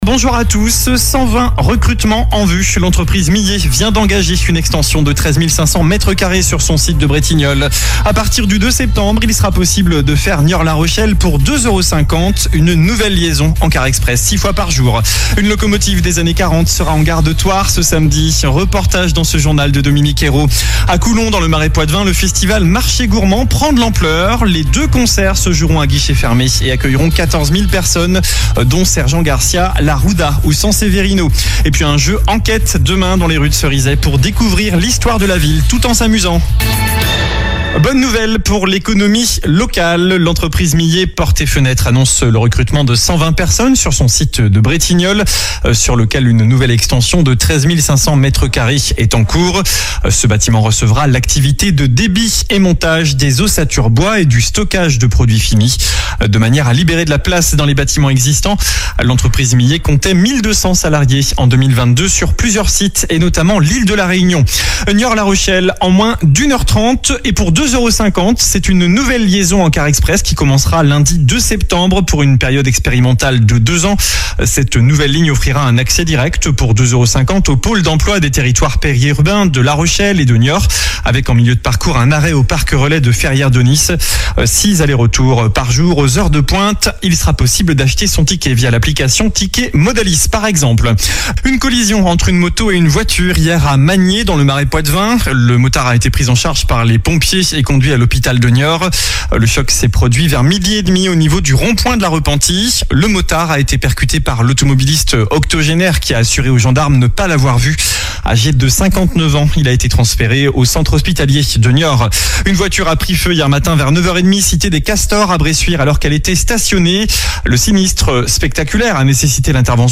JOURNAL DU MERCREDI 21 AOÛT